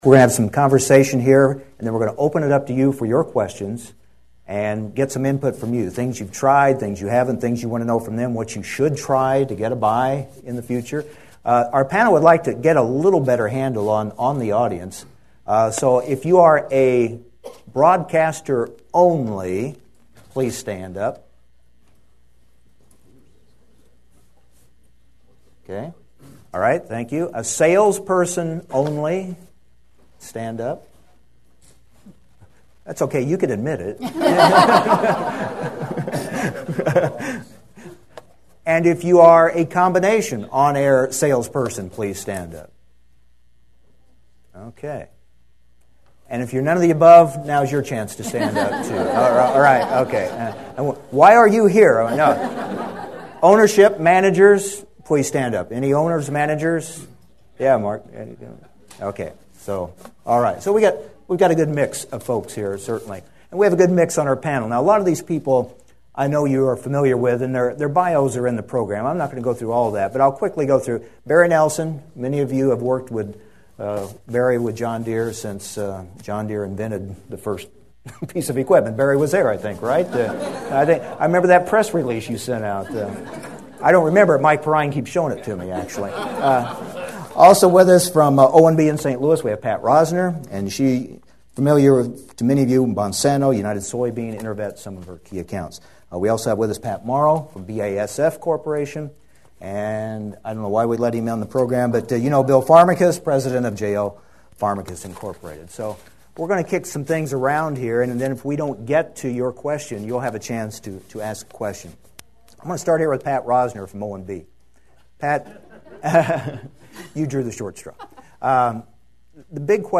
NAFB Convention Opening Panel
Listen to the panelists answer that question here (after a brief introduction): nafb-08-value.mp3